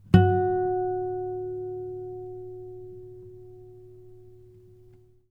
strings_harmonics
harmonic-01.wav